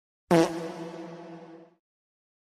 Suara kentut 1 detik
Kategori: Suara meme
Ini adalah efek suara meme yang sering dipakai banyak orang untuk mengisi suara video lucu.
suara-kentut-1-detik-id-www_tiengdong_com.mp3